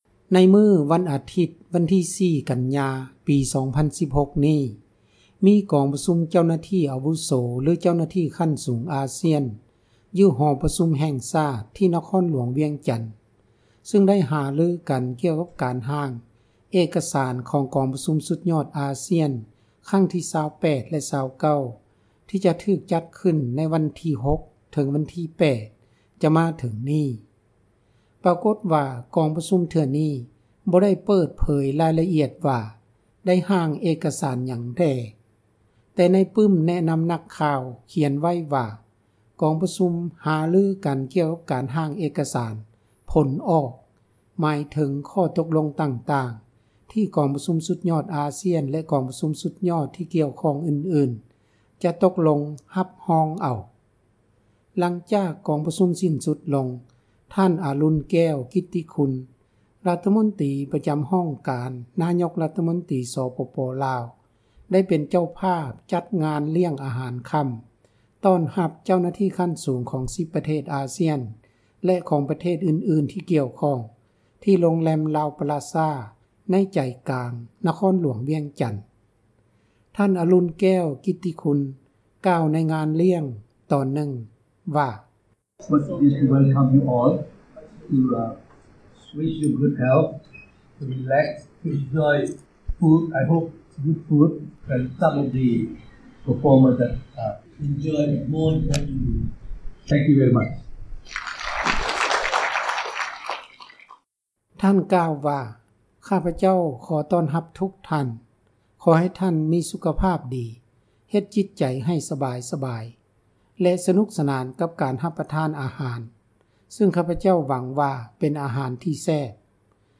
ທ່ານ ອາລຸນແກ້ວ ກິດຕິຄຸນ ກ່າວໃນງານລ້ຽງ ຕອນນຶ່ງວ່າ: